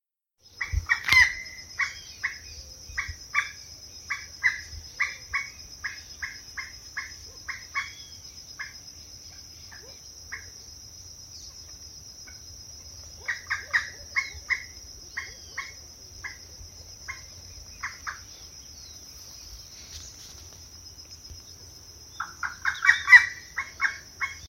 Grey-cowled Wood Rail (Aramides cajaneus)
Detailed location: Laguna Cantera
Condition: Wild
Certainty: Recorded vocal
chiricote.mp3